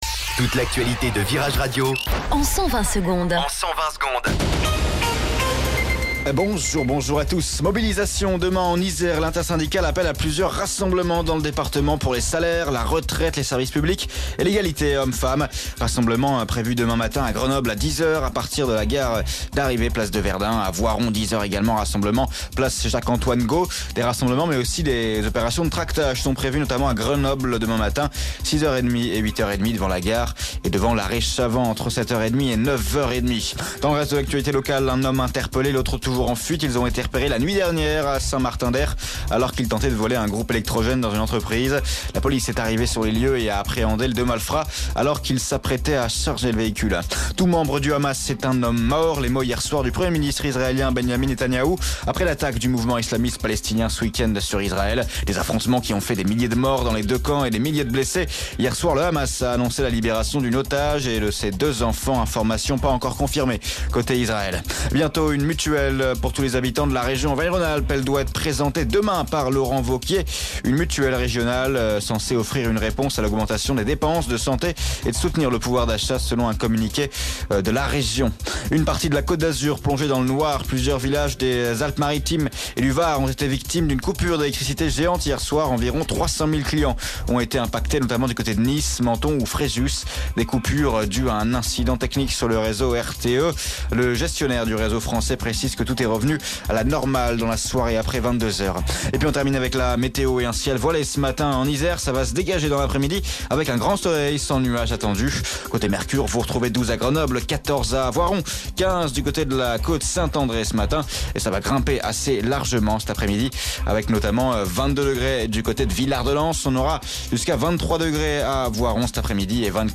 Flash Info